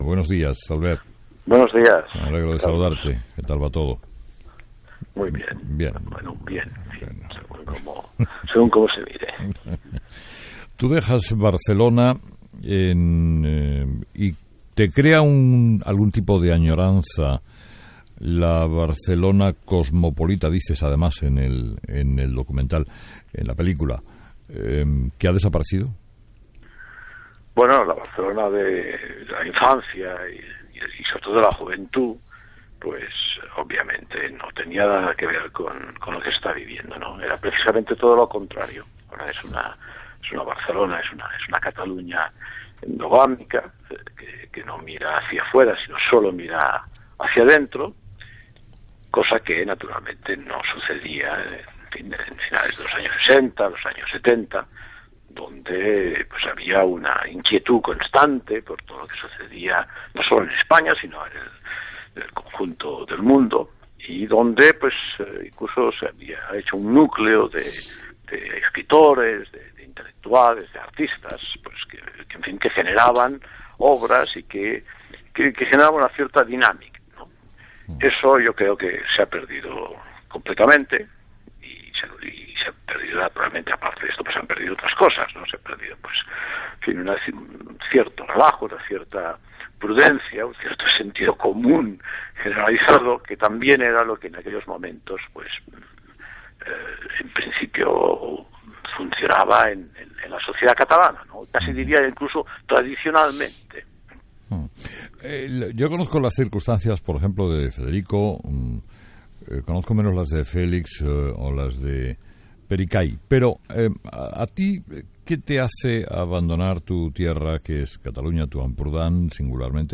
Entrevista a Albert Boadella
Entrevistado: "Albert Boadella"